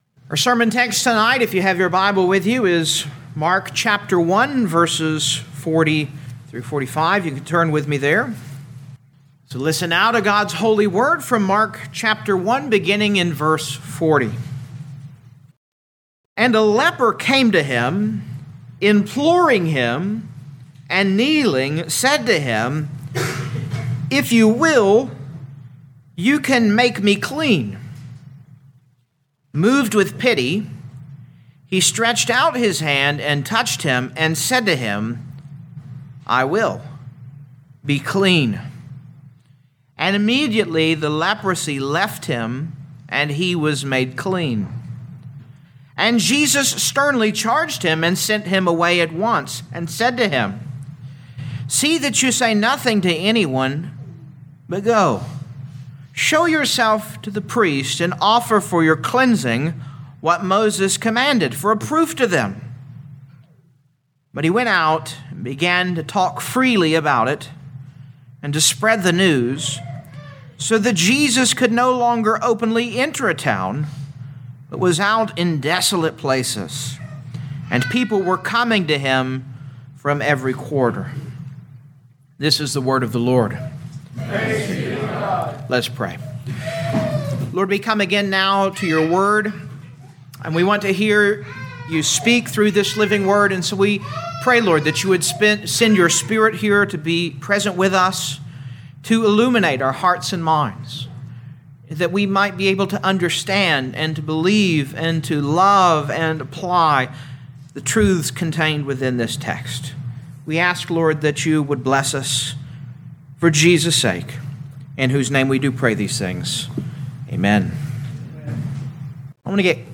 2025 Mark Evening Service Download
You are free to download this sermon for personal use or share this page to Social Media. Christ Can Cleanse Scripture: Mark 1:40-45